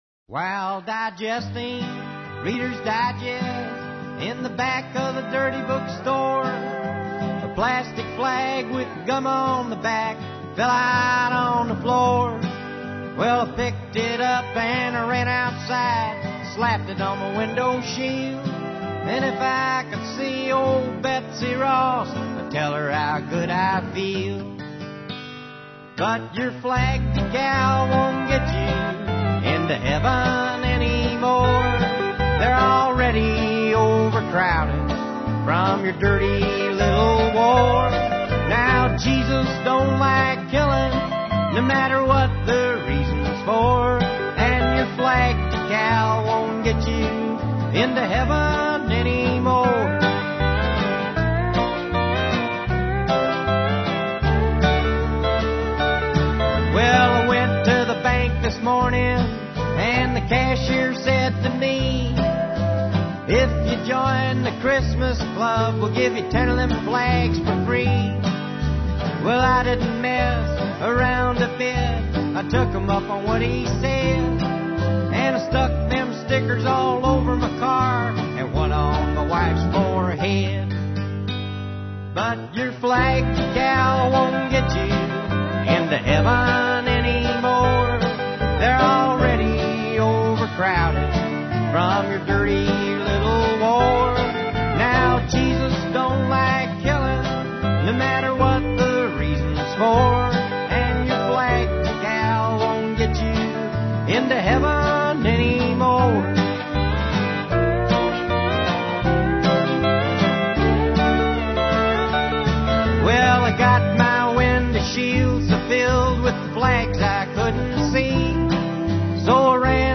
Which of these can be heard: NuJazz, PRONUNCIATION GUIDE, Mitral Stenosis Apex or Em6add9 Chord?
NuJazz